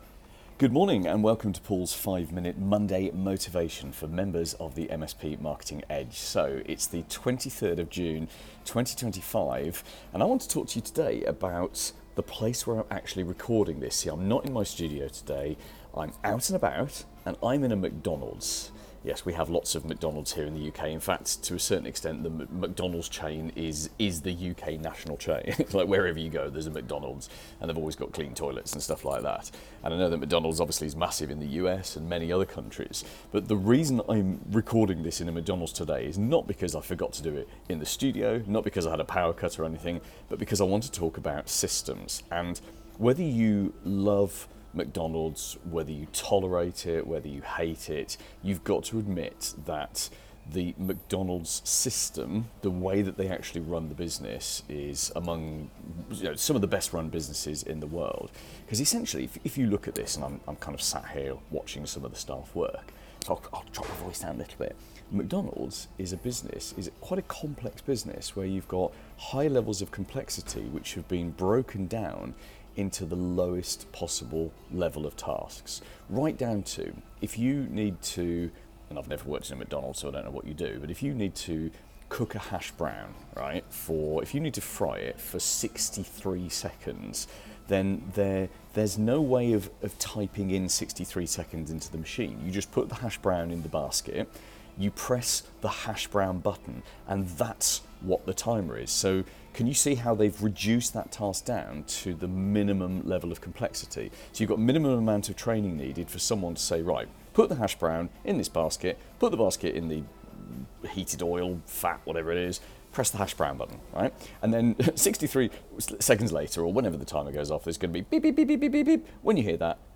I'm out on the road today and recording this episode in a McDonald's. Why? Because to me it's a masterclass in how to reduce complex procedures down into simple tasks that almost anyone can do.